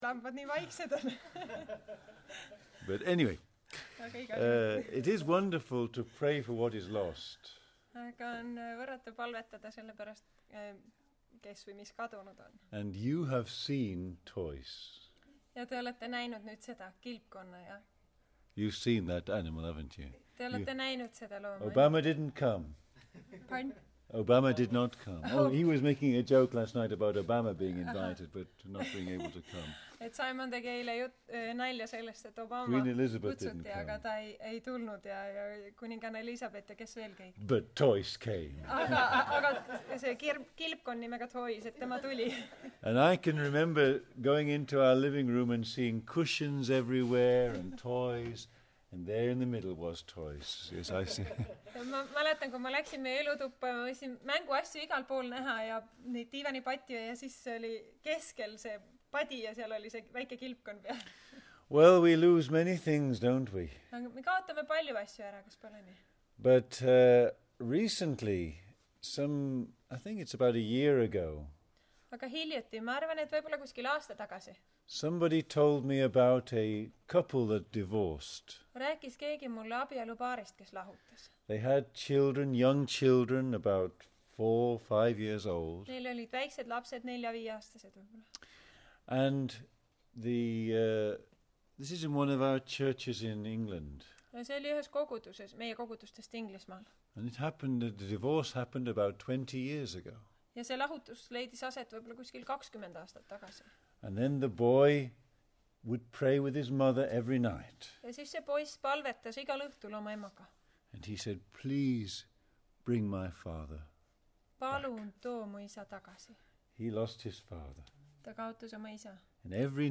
Jutlused Siin võid kuulata jutlused Võhma Kristlik Kogudusest.